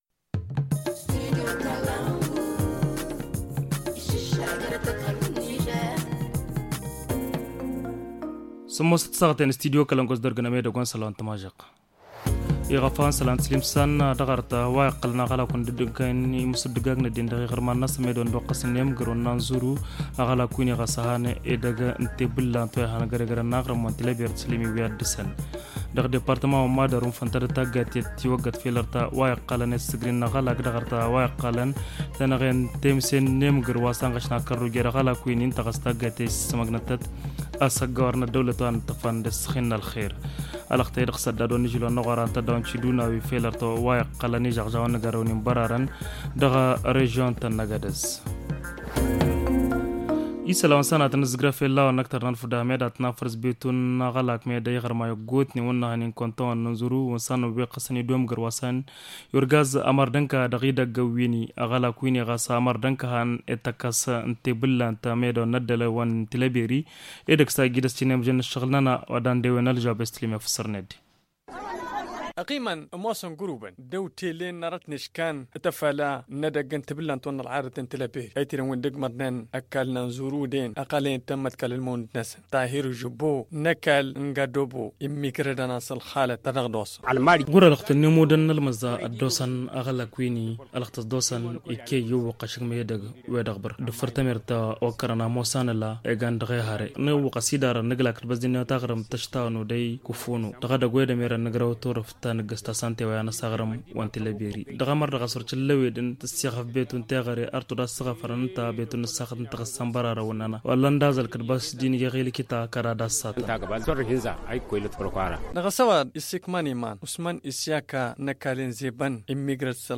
Le journal du 18 mai 2021 - Studio Kalangou - Au rythme du Niger